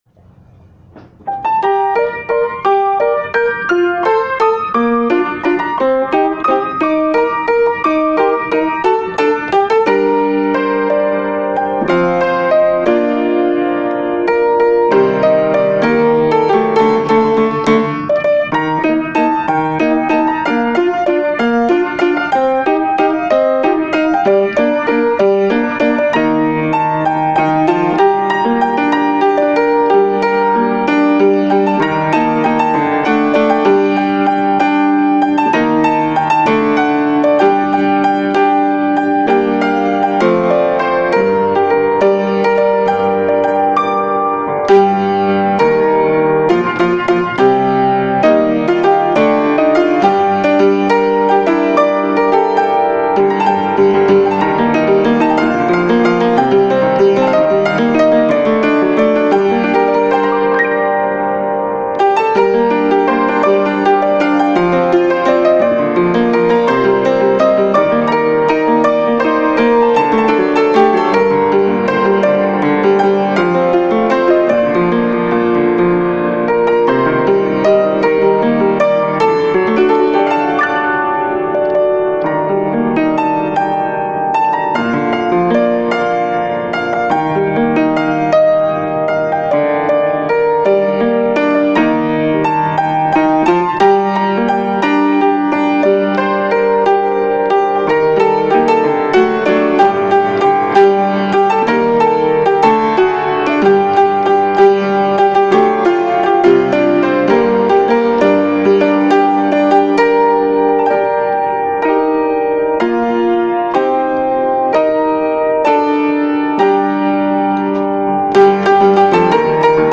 עיבוד יפה לפסנתר
איכות הקלטה נמוכה ומטה, וחבל.
מנצל הרבה מאוד ממה שאפשר לתת בכלי בודד ובנגן ספציפי.